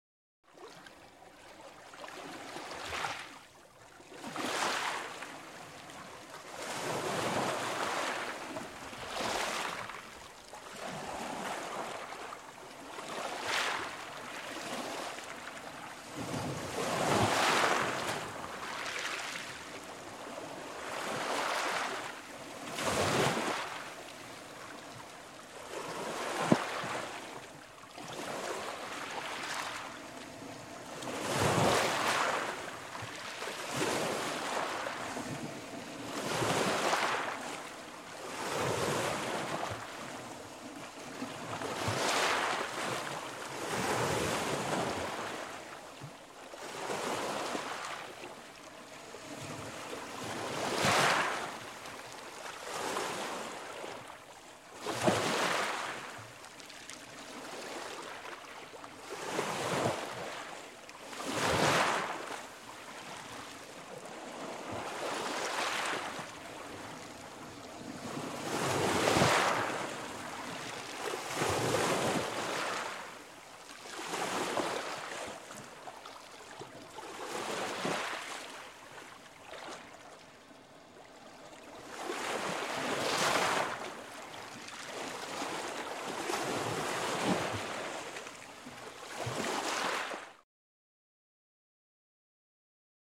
Tiếng Sóng biển Vỗ vào bờ
Thể loại: Tiếng thiên nhiên
Description: Tiếng Sóng biển Vỗ vào bờ là hiệu ứng tiếng sóng biển dào dạt đập vào bờ, âm thanh của biển cả khi hàng ngàn con sóng nối đuôi nhau vỗ vào bờ, hiệu ứng tiếng nước biển xô vào bờ cát trắng, tiếng sóng biển dạt dào như sự vỗ về của thiên niên làm dịu đi cái nóng mùa hè, âm thanh của biển cả kỳ vĩ thu gọn vào những con sóng nối đuôi nhau vỗ về bờ cát.
Tieng-song-bien-vo-vao-bo-www_tiengdong_com.mp3